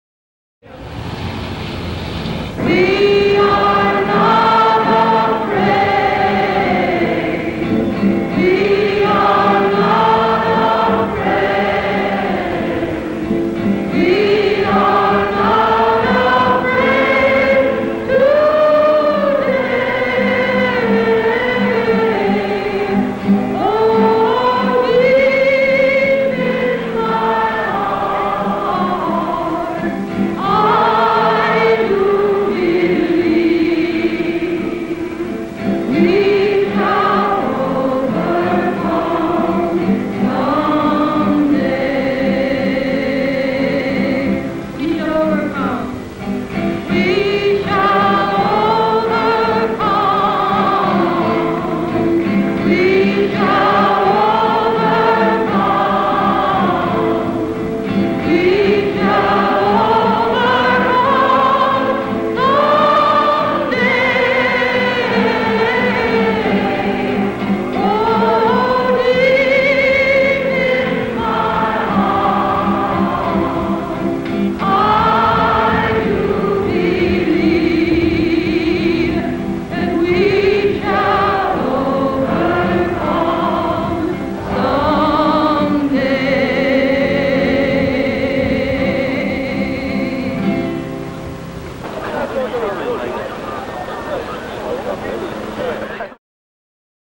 Picture of Music: We Shall Overcome Civil Rights March on Washington, D.C. Vocalist Joan Baez.
Joan Baez performs "We Shall Overcome" at the March on Washington for Jobs and Freedom in Washington D.C. on August 28, 1963.
"We Shall Overcome" is a gospel song which became a protest song and a key anthem of the Civil Rights Movement.
Joan Baez performs We Shall Overcome at the March on Washington.mp3